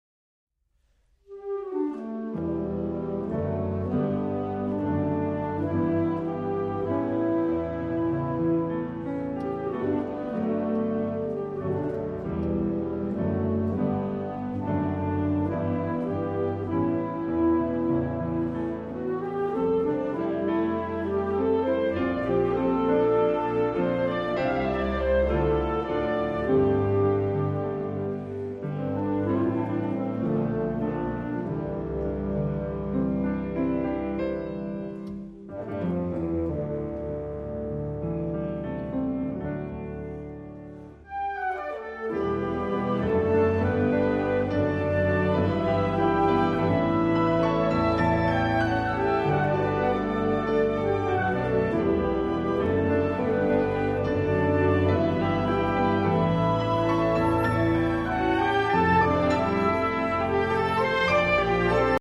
concerto for saxophone and orchestra